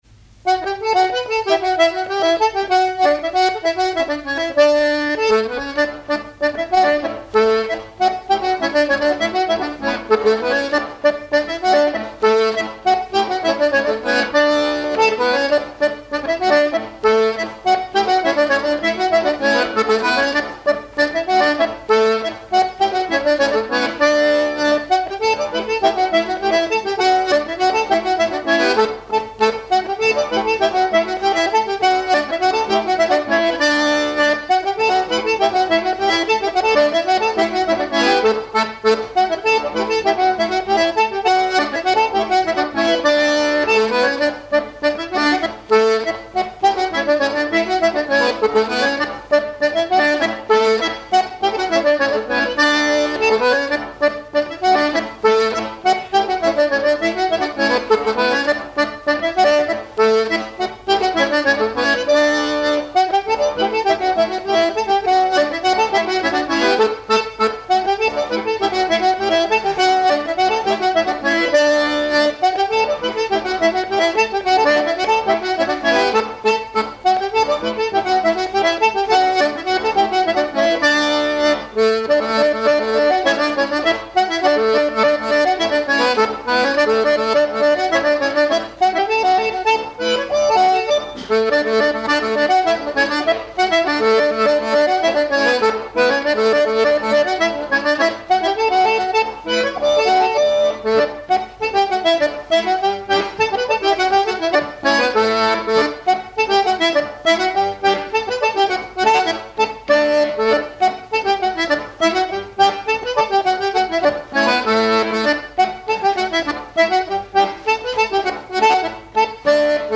Enchaînement de quatre pas d'été (version rapide)
airs de danse à l'accordéon diatonique
Pièce musicale inédite